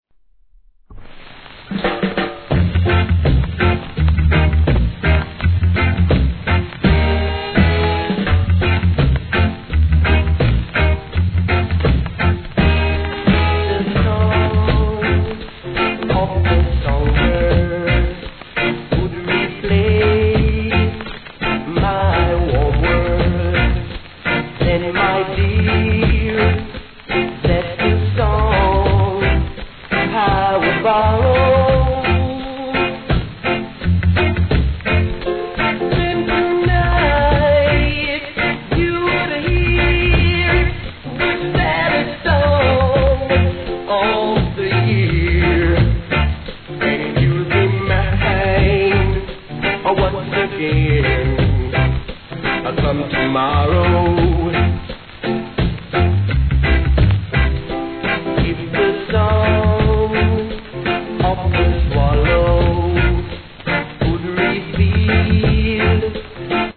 序盤にサー目立ちます
REGGAE